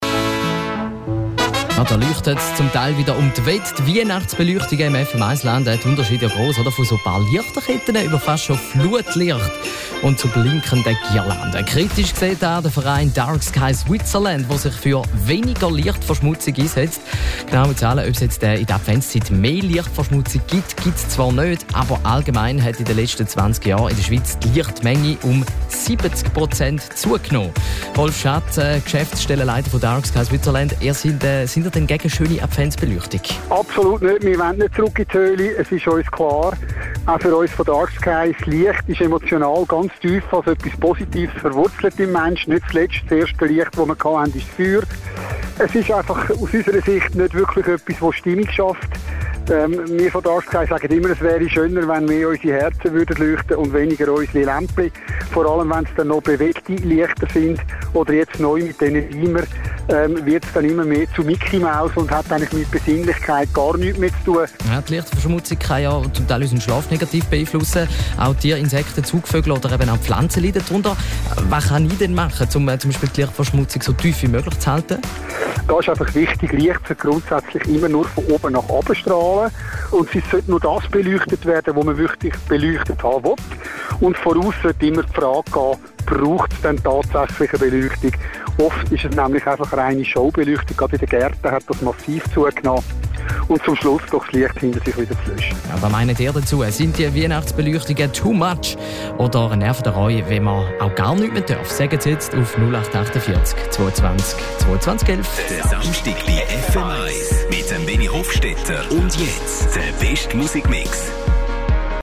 Ist die Lichtverschmutzung in der Adventszeit wegen der Weihnachtsbeleuchtung noch grösser? FM1 hat einen Experten gefragt.